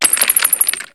Cri de Crabicoque dans Pokémon HOME.